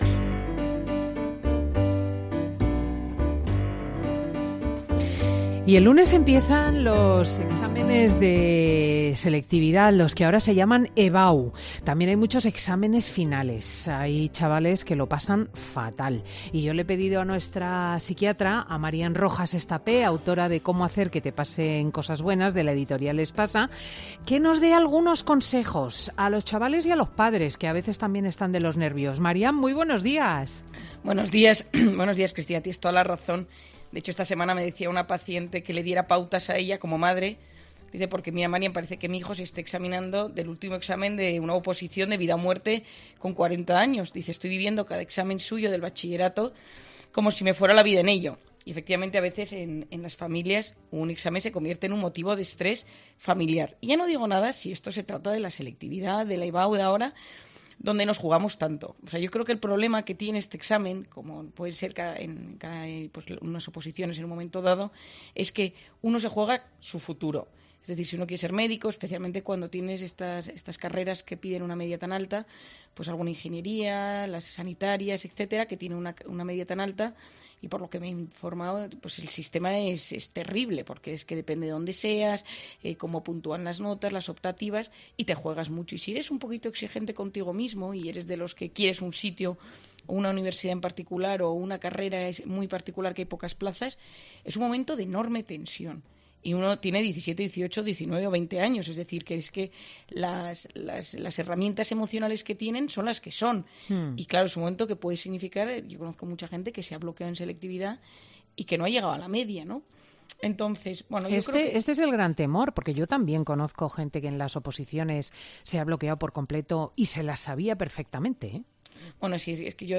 Hablamos con nuestra psiquatra de cabecera sobre cómo podemos ayudar a nuestros seres queridos a afrontar los exámenes de la EBAU sin caer en los...